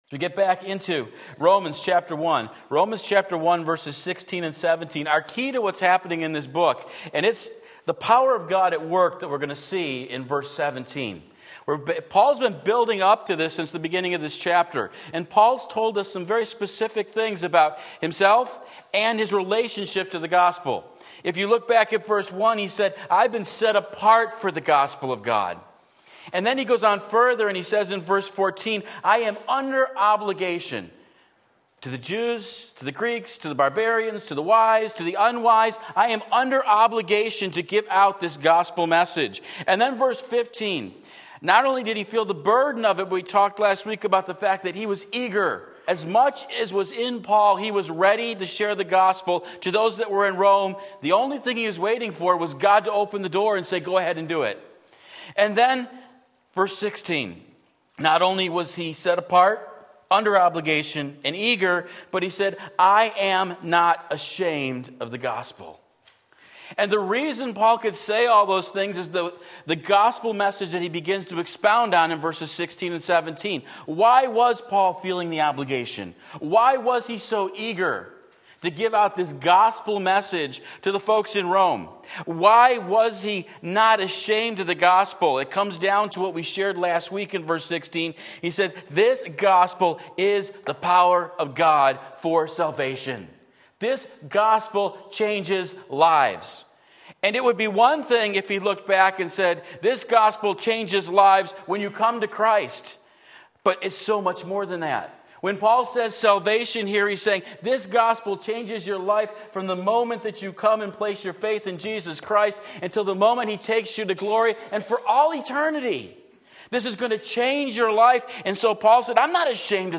Sermon Link
The Power of God at Work Romans 1:16-17 Sunday Morning Service